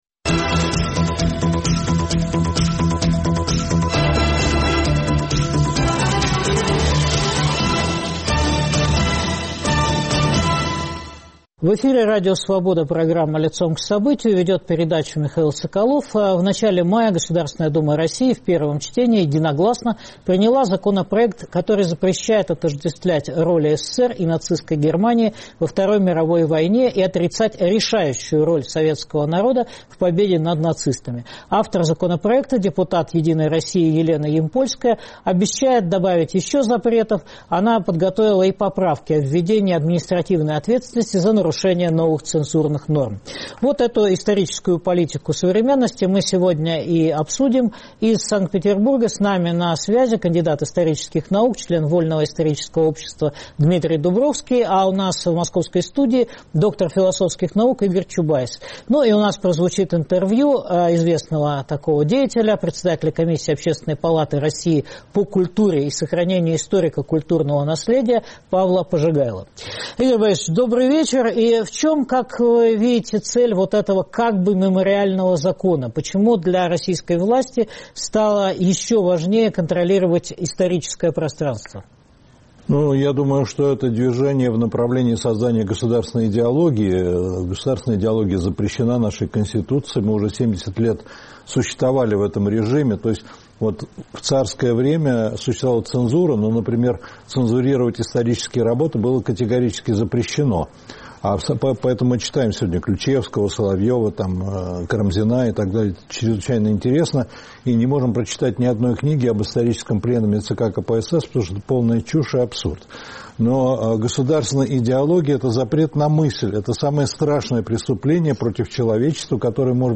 Интервью председателя Комиссии Общественной палаты РФ по культуре и сохранению историко-культурного наследия Павла Пожигайло.